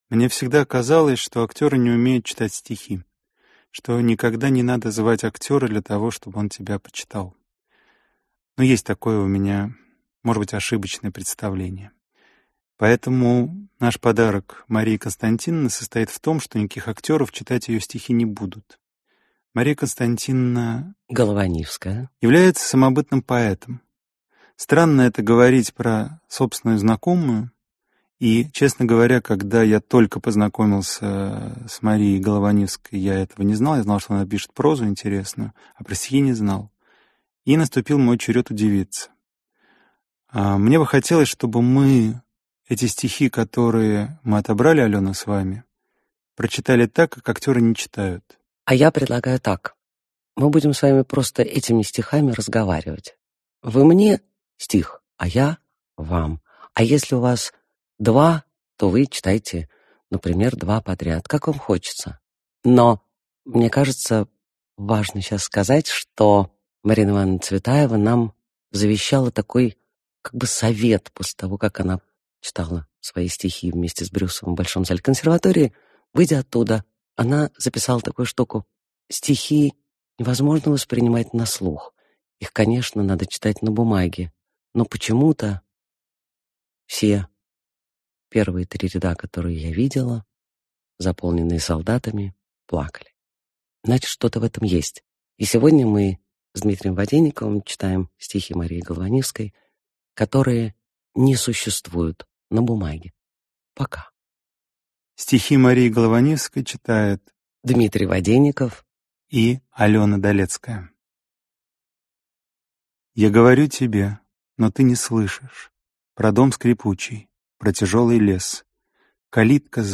Аудиокнига Зной. Стихи | Библиотека аудиокниг